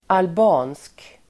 Uttal: [alb'a:nsk]